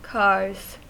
Ääntäminen
Ääntäminen US Tuntematon aksentti: IPA : car IPA : /ˈkɑɹz/ IPA : /ˈkɑːz/ Haettu sana löytyi näillä lähdekielillä: englanti Käännöksiä ei löytynyt valitulle kohdekielelle.